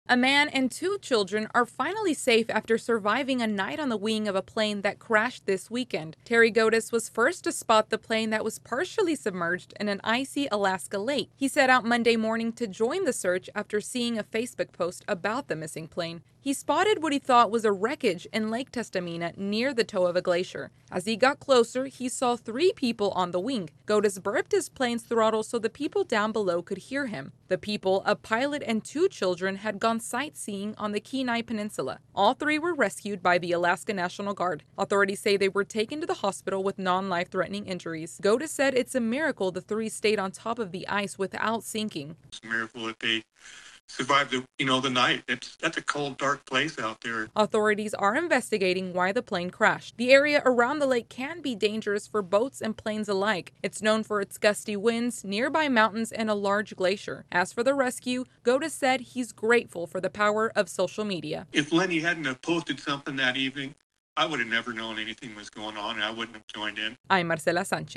AP correspondent reports on how an Alaskan man helped in the rescue of three people stranded after a plane crash.